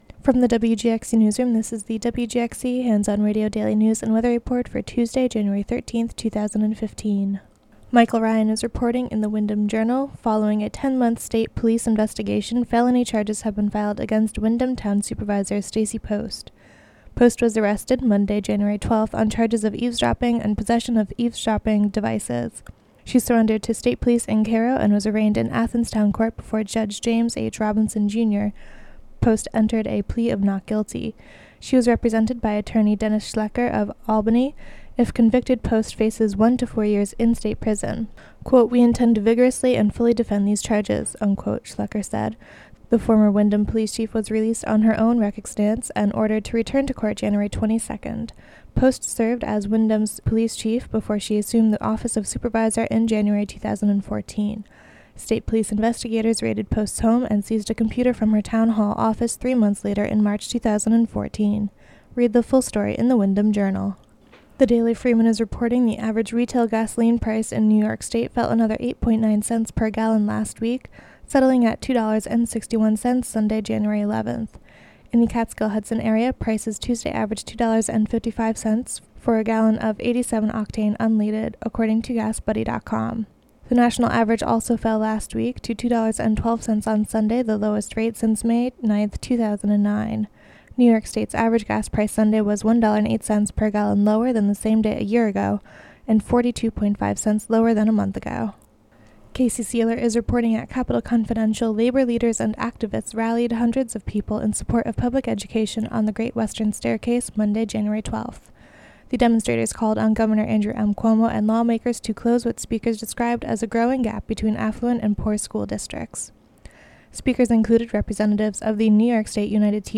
Local news and weather for Tuesday, January 13, 2015.